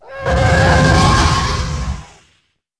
roar2.wav